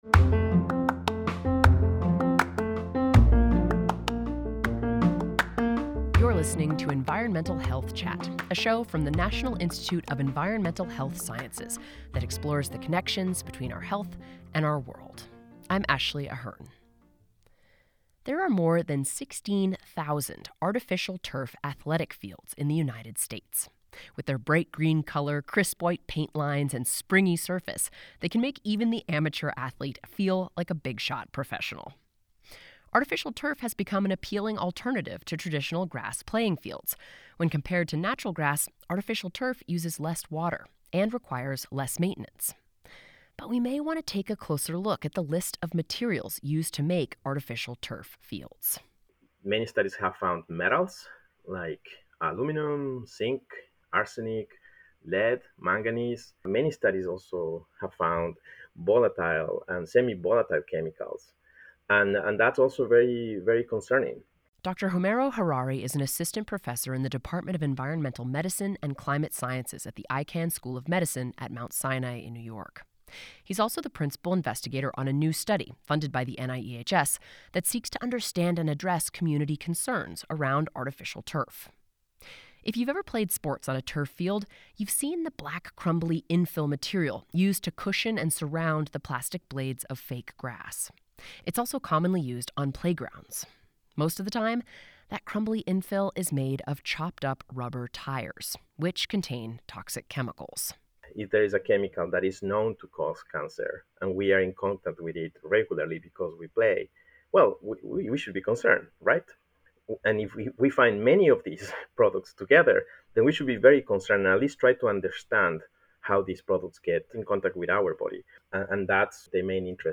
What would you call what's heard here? Interviewee: